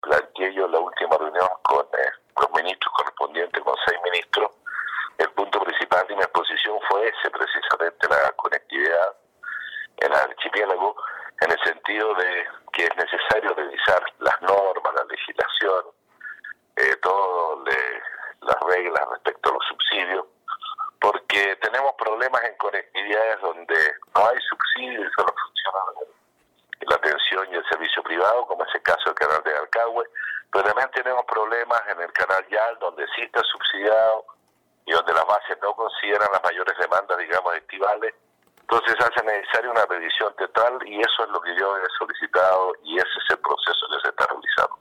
Así se desprende por otra parte, de las palabras del intendente Harry Jurgensen, quien planteó estas demandas en el consejo de gabinete ampliado realizado recientemente por el presidente Sebastián Piñera, como lo expresó en entrevista con radio Estrella del Mar de Achao.